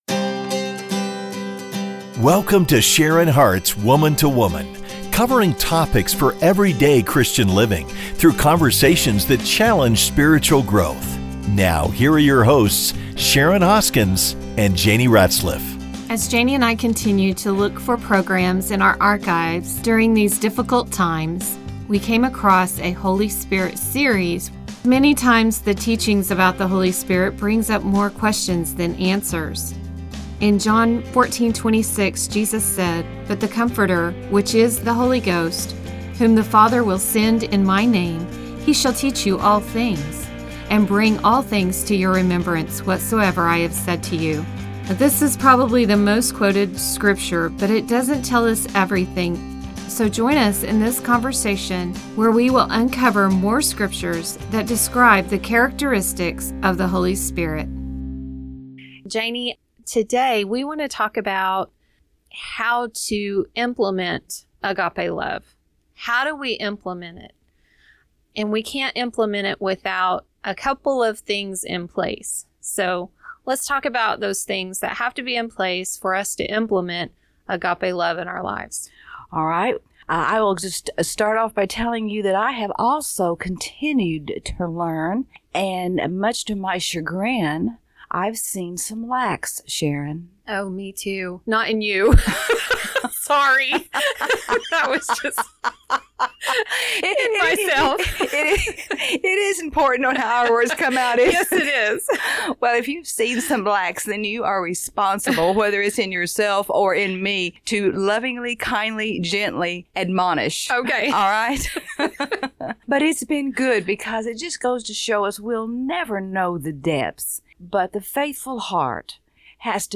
Join in the conversation as we uncover more scriptures that describe The Characteristics of the Holy Spirit .